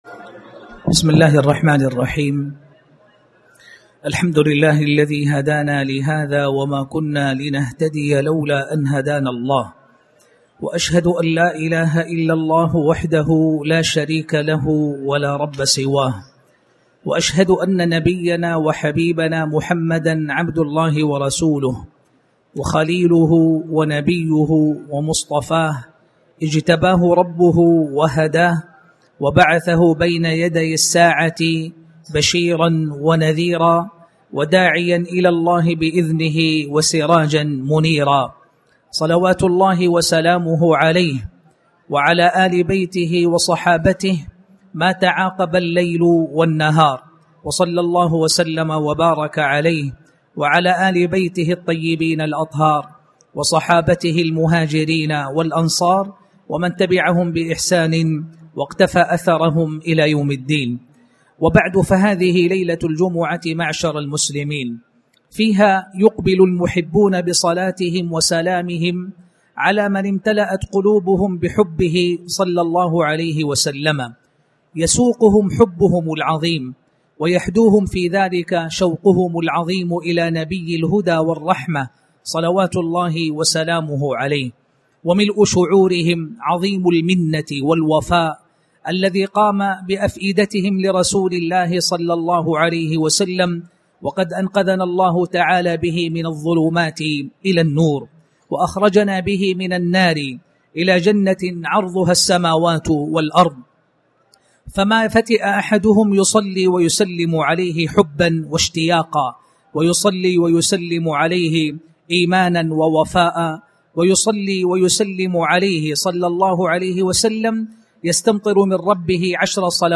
تاريخ النشر ٢٦ ربيع الأول ١٤٣٩ هـ المكان: المسجد الحرام الشيخ